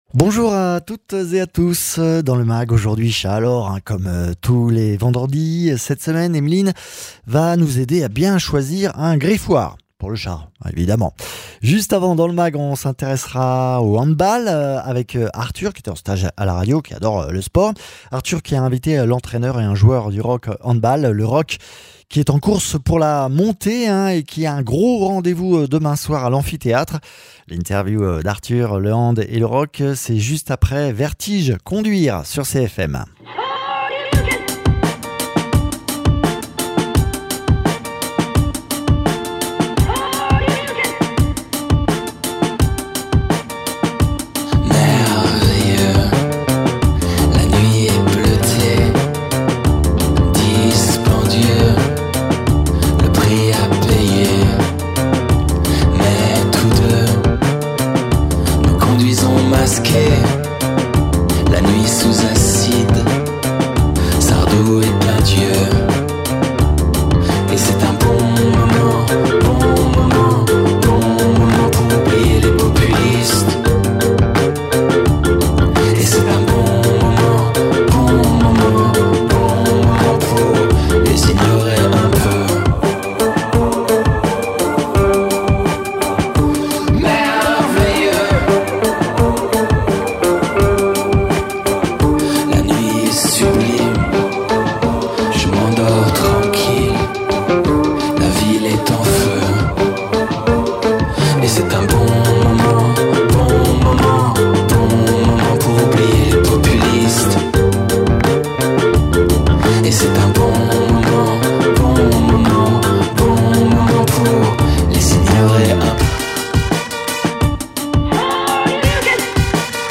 comportementaliste félin